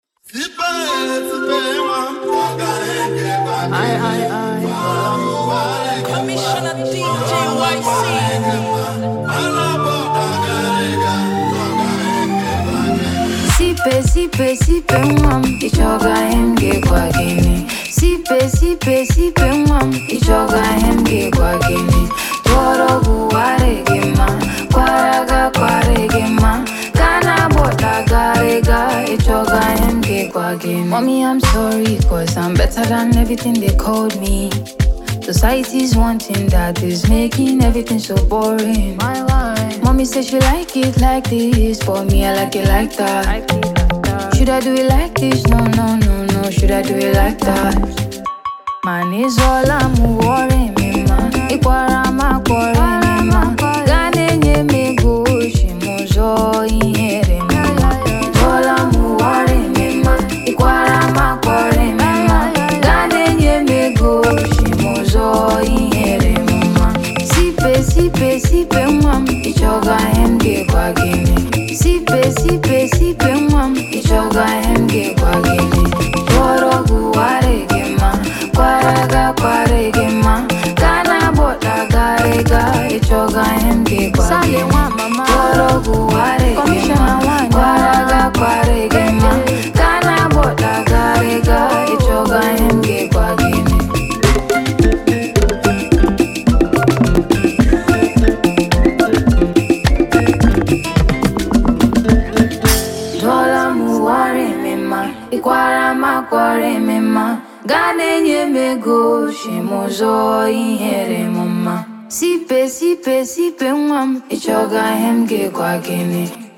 Fast Rising imo-born vocalist
melodious hit single